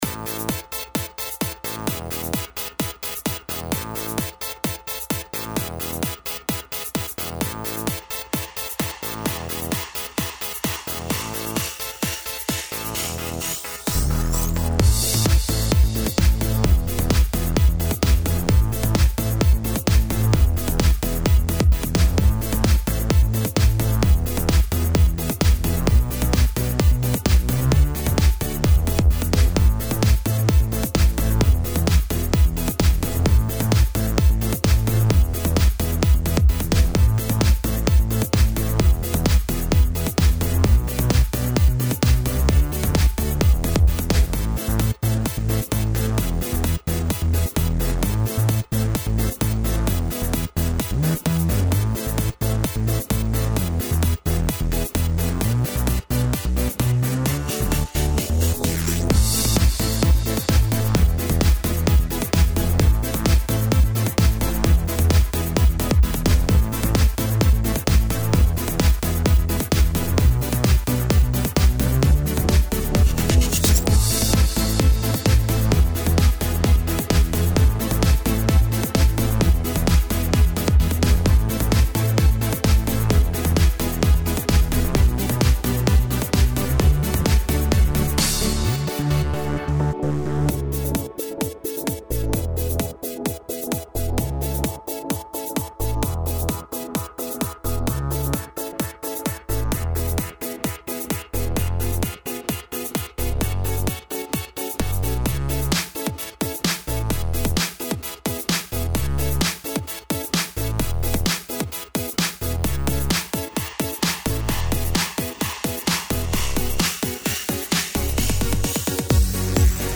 This is a normal post Very jolly
I can see that going down well in the clubs.